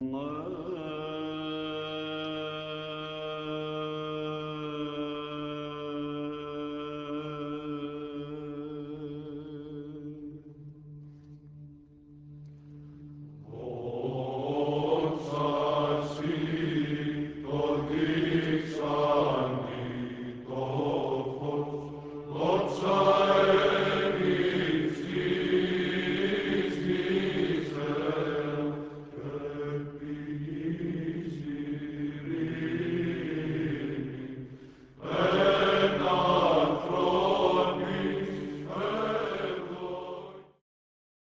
Slow Doxology, Second mode